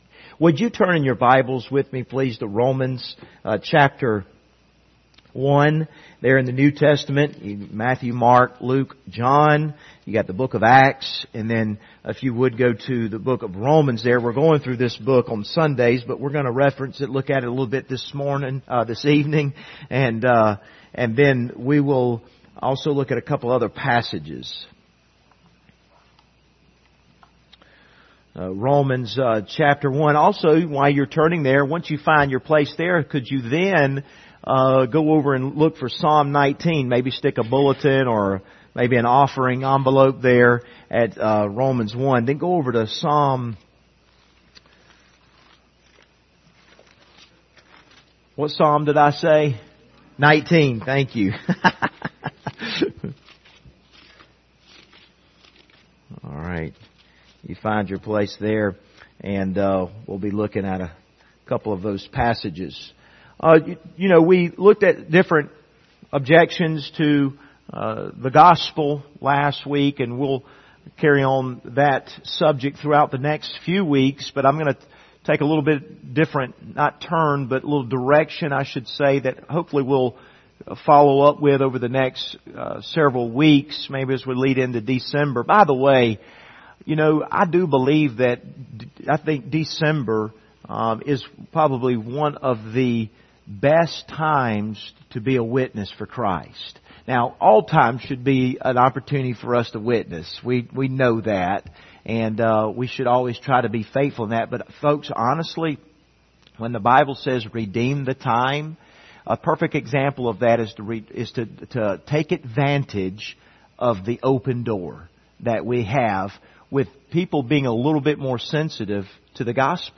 Service Type: Wednesday Evening Topics: apologetics